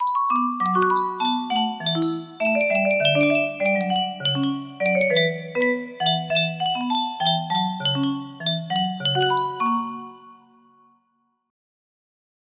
winds of the.. wait what? music box melody
Full range 60